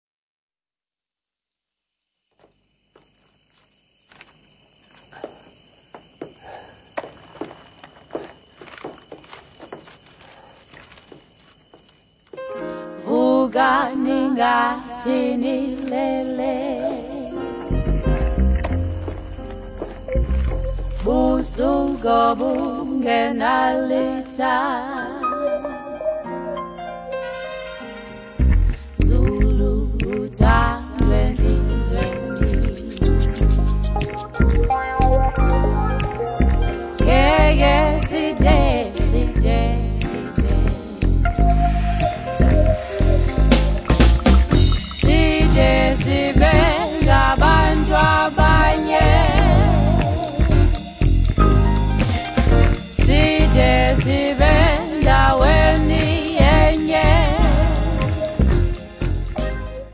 Lei stessa definisce il suo stile Modern African Soul.
Sapori chill out- nujazz